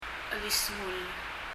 [ɛlisməl] のように聴こえますが、 じーっと聴くとやはり [ɛlisŋəl] です。